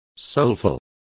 Complete with pronunciation of the translation of soulful.